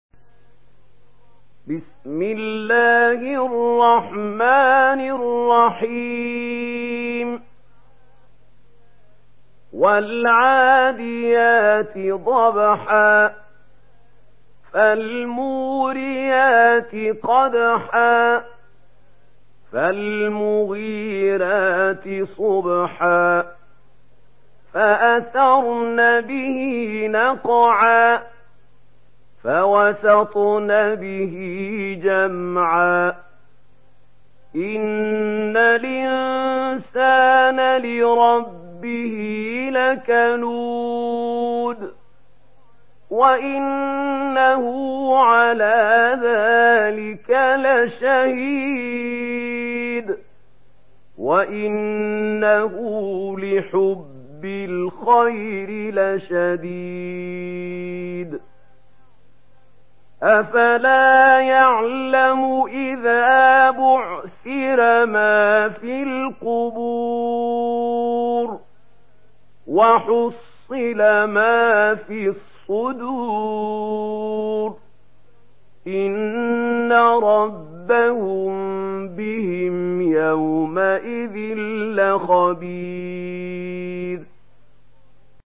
Surah Adiat MP3 in the Voice of Mahmoud Khalil Al-Hussary in Warsh Narration
Surah Adiat MP3 by Mahmoud Khalil Al-Hussary in Warsh An Nafi narration. Listen and download the full recitation in MP3 format via direct and fast links in multiple qualities to your mobile phone.